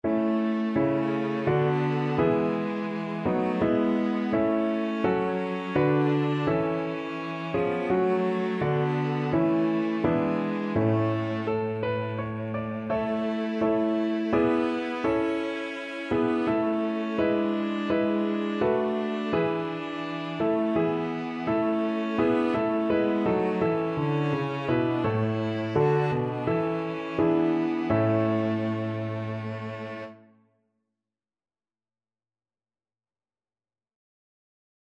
ViolaCelloPiano
3/4 (View more 3/4 Music)
Piano Trio  (View more Easy Piano Trio Music)